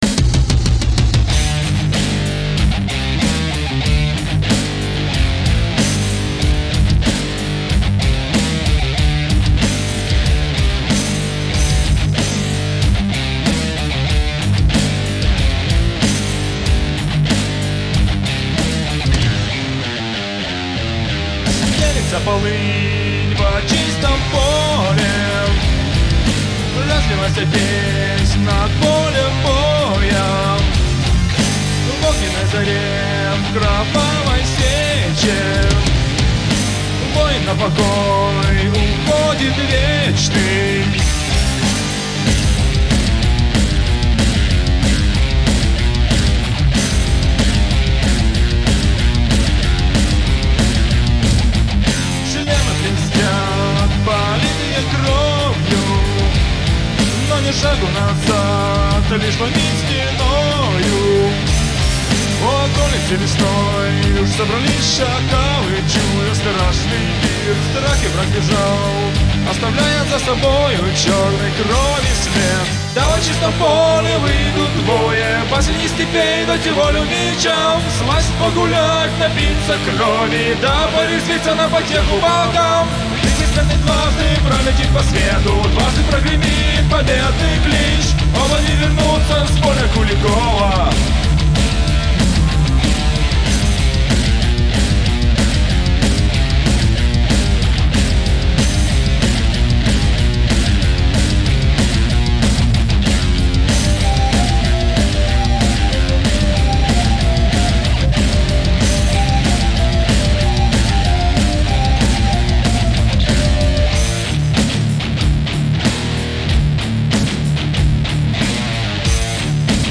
Metal music [29]